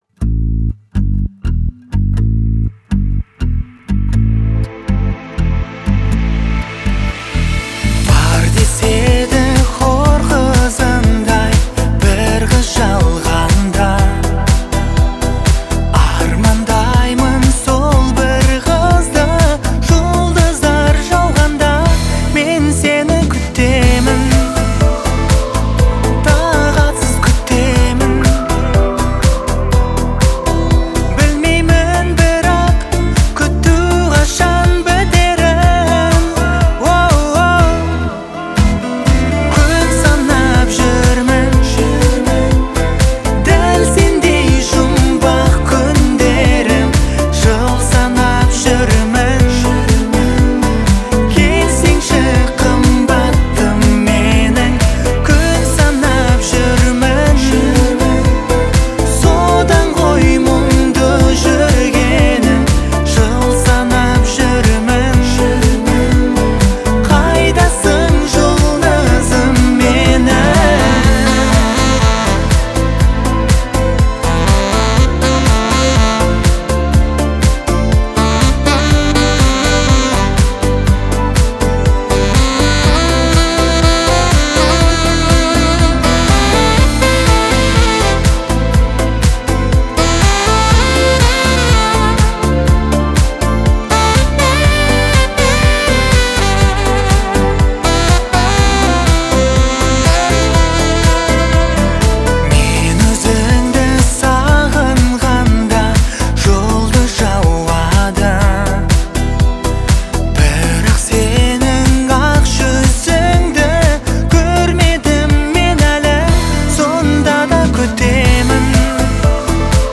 которая сочетает в себе элементы поп-музыки и фольклора.